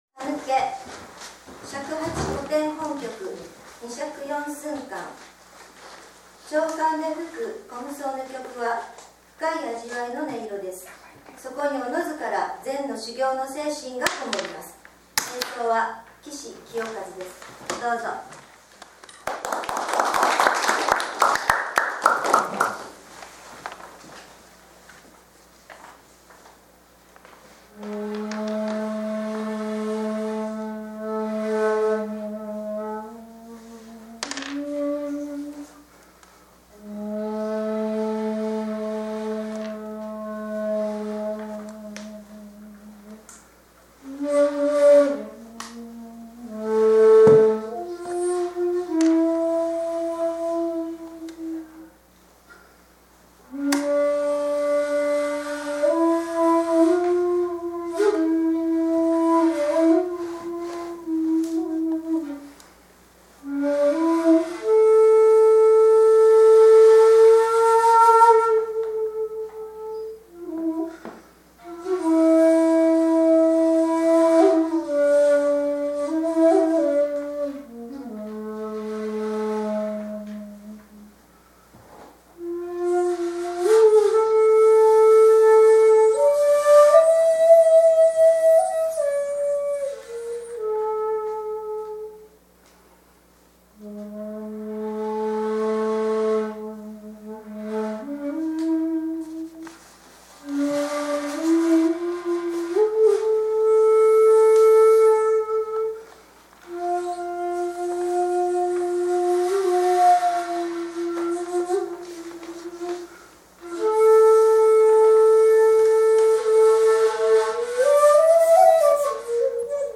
〈紅葉ふみわけ鳴く鹿の･･･〉と題しまして今年も恒例の尺八教室・秋の演奏会を開催いたしました。
会場は例年の通り江戸時代の商家で泉佐野市の有形文化財に指定されている「新川家」です。
二尺四寸管
長管で吹く虚無僧の曲は深い味わいの音色です。